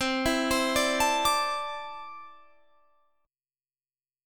C6add9 chord